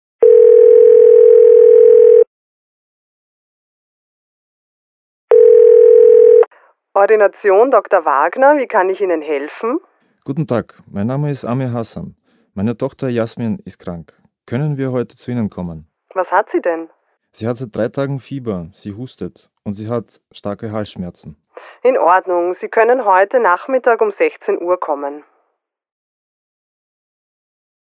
„Gespräch b"
DLM_Dialog_2.mp3